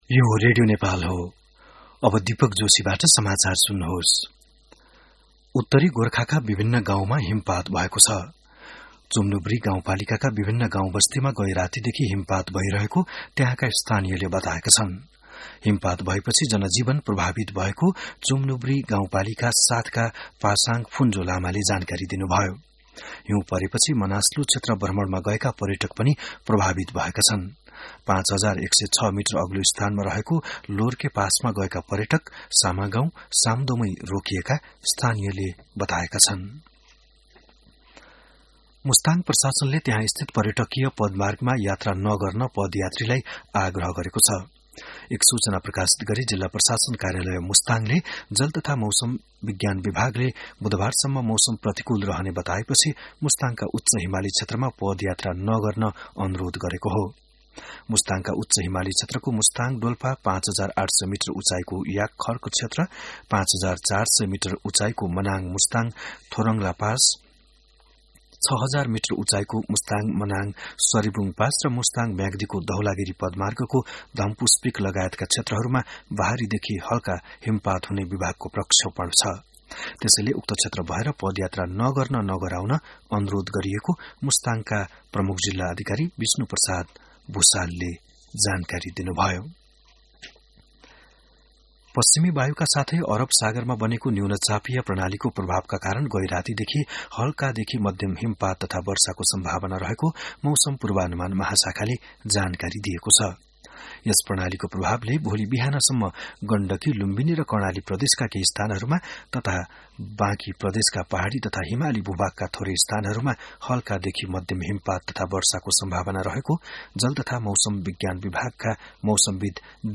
बिहान १० बजेको नेपाली समाचार : ११ कार्तिक , २०८२